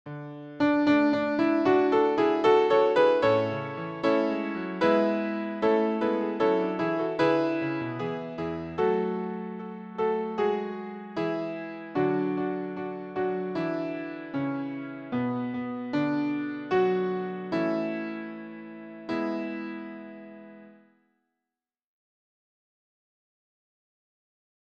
Sacré. Chœur.
Sacré ; Chœur
Orgue (1 partie(s) instrumentale(s))
Tonalité : la majeur